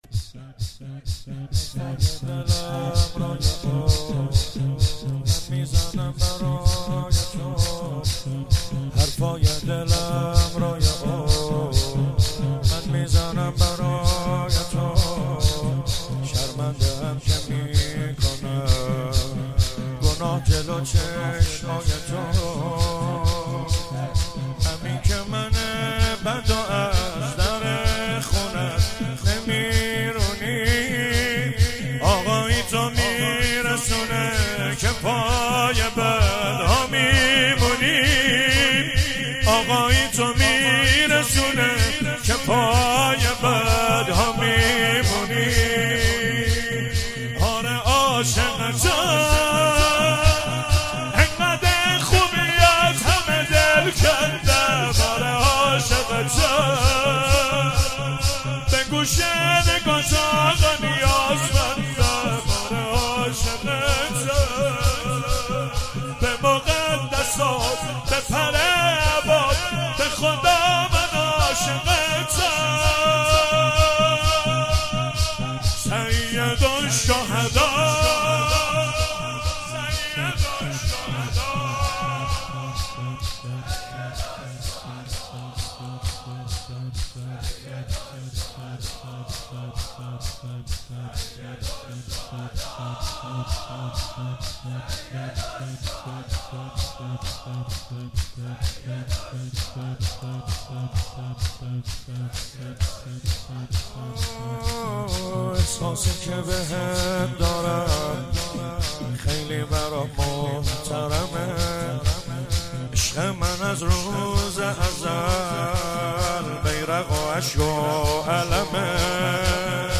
نوحه محرم